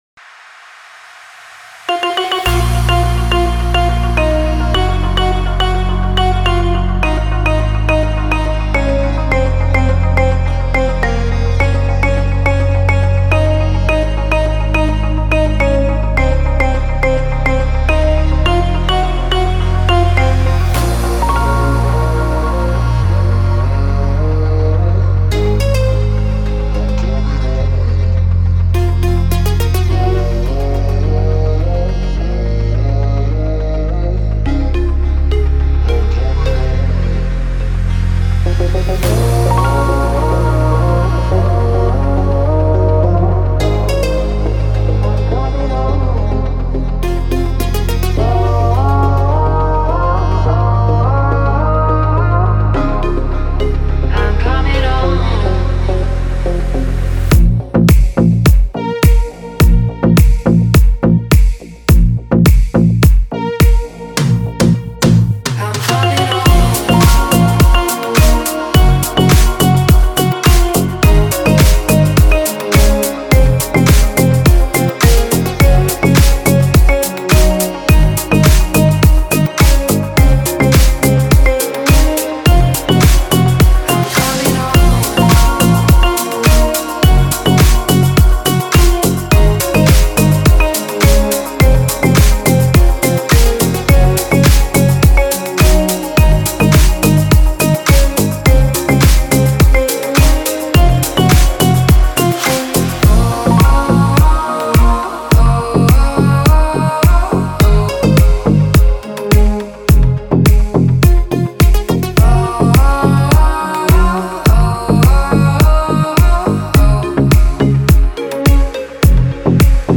это захватывающая композиция в жанре техно